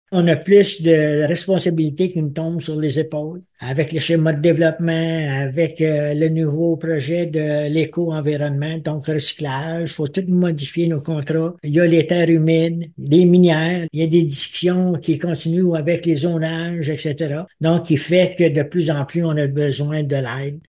Kazabazua crée un premier poste de directeur général adjoint. Cette future embauche s’explique, entre autres, par l’augmentation du nombre de dossiers que doit gérer la Municipalité de 1 100 habitants. Robert Bergeron, maire de Kazabazua, énumère quelques-uns de ces dossiers :